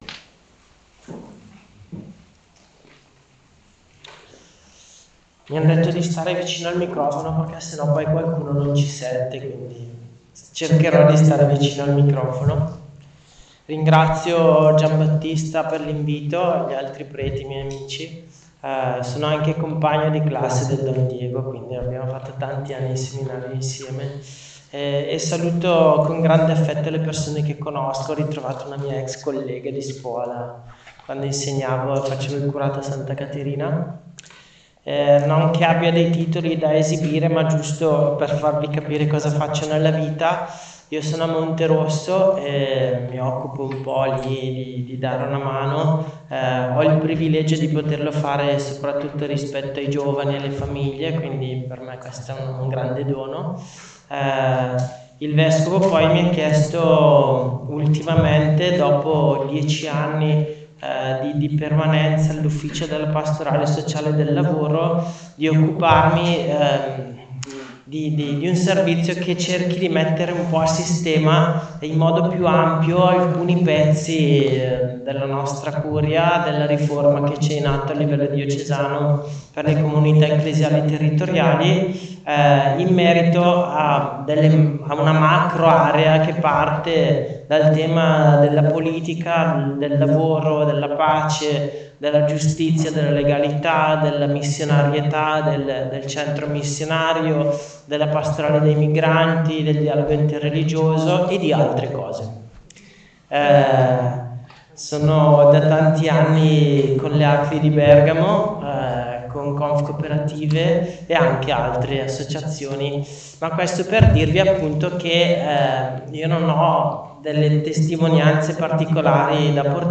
Interventi della giornata